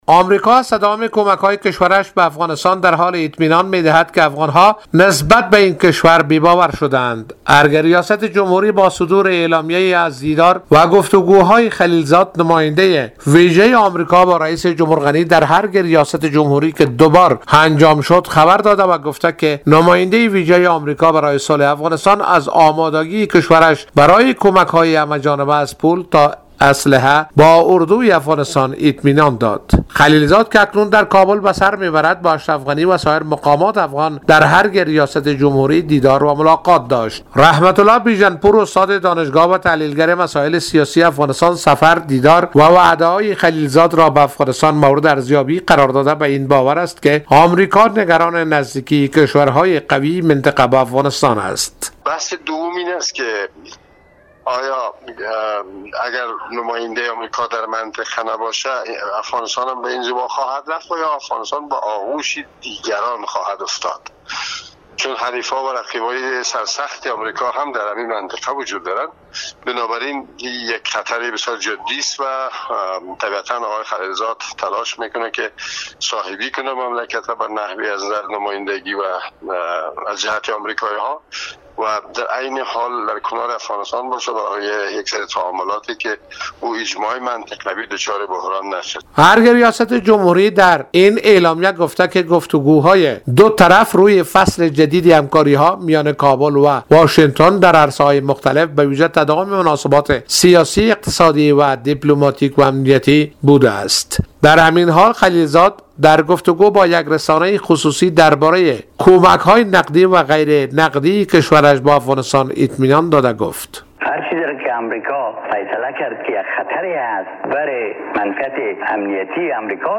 به گزارش خبرنگار رادیودری ، ارگ ریاست جمهوری افغانستان می گوید، دو طرف روی فصل جدید همکاری ها میان کابل -واشنگتن در عرصه های مختلف و تداوم مناسبات سیاسی، اقتصادی، دیپلماتیک وامنیتی گفتگو کردند.